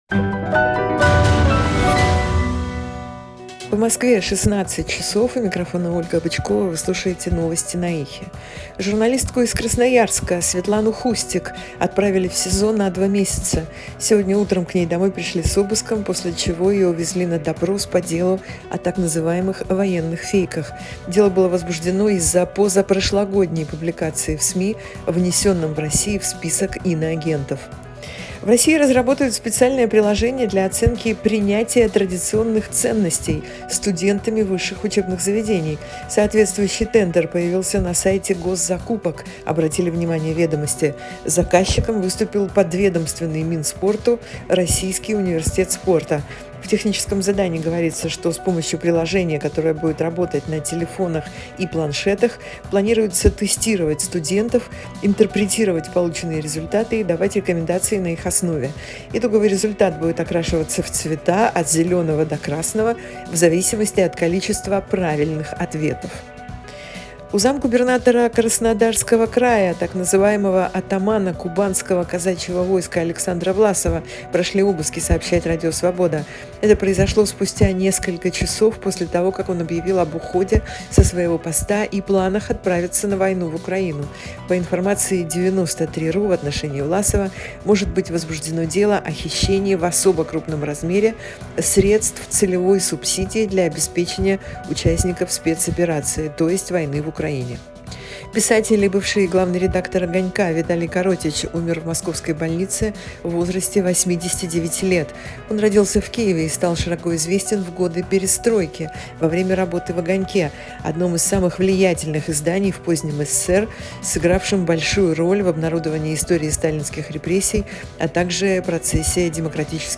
Новости 16:00